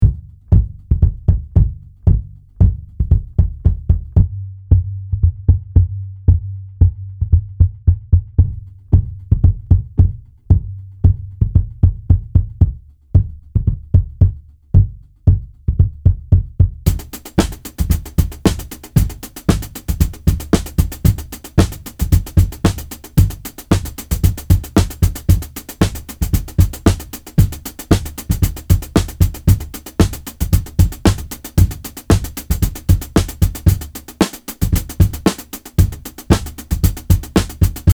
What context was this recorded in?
Here is a quick audio example of the subkick in action. Bars 1-2 : Internal kick mic only Bars 3-4 : Subkick only Bars 5-6 : Internal mic + Subkick Bars 7-8 : Internal mic + Subkick (with gate on subkick) Bars 9-10 : Full kit, no subkick Bars 11 – end : Full kit with Subkick example-subkick.mp3